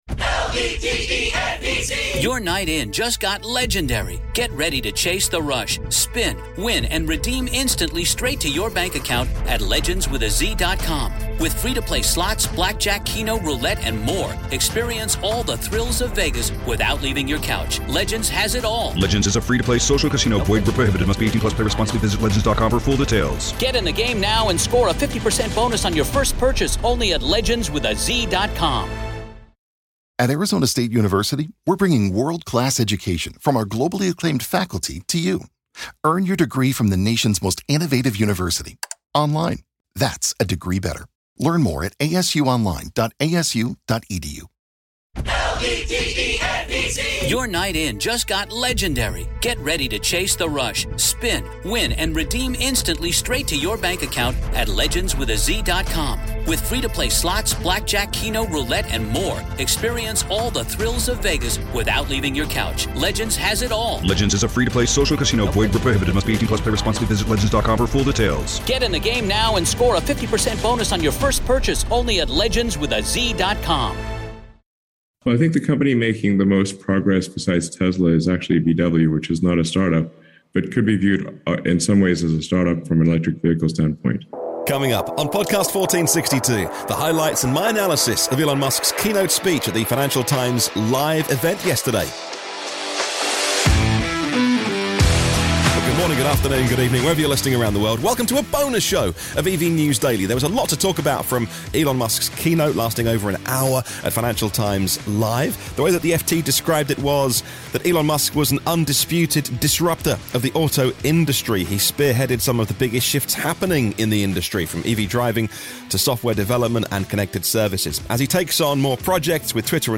A bonus episode featuring my highlights and personal analysis of Elon Musk's keynote appearance at FT Live in London. Musk appeared via video link with fellow Tesla co-founder, and former Chief Technical Officer, JB Straubel on stage.